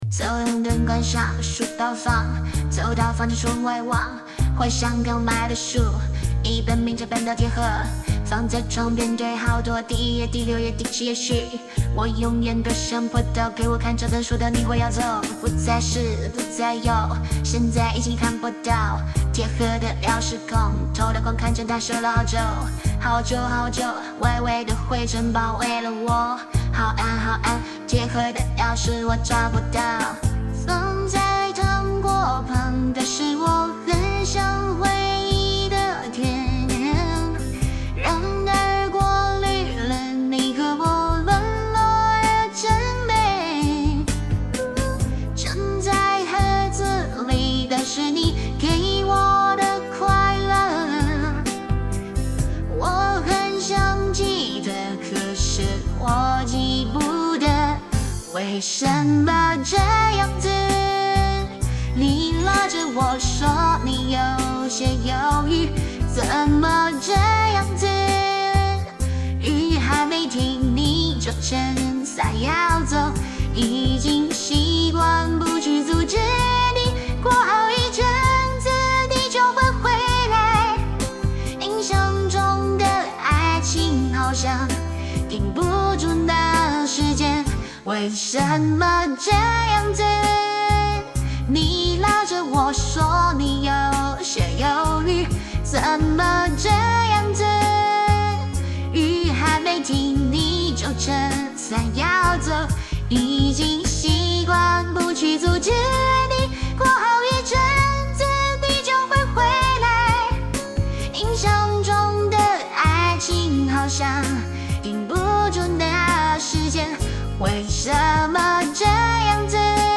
RVC模型 《蜡笔小新》妮妮角色RVC模型